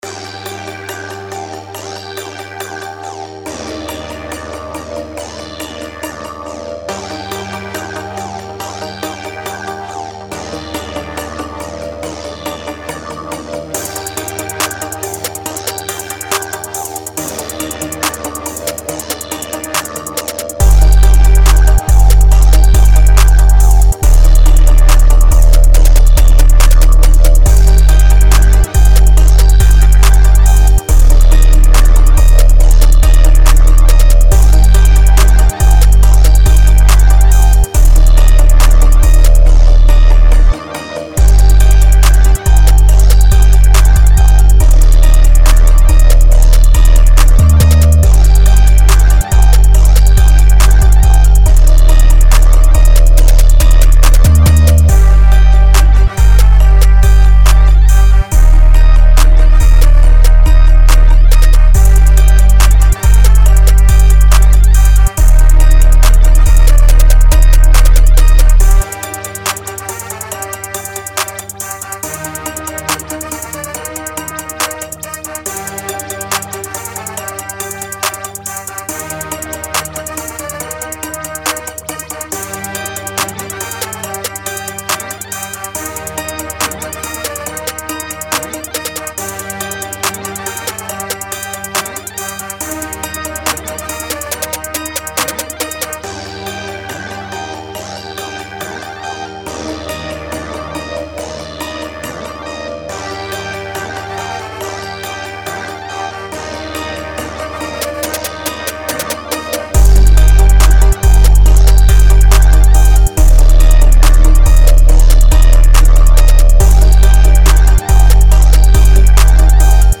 Trap Rage Beats
rage Мрачный 139 BPM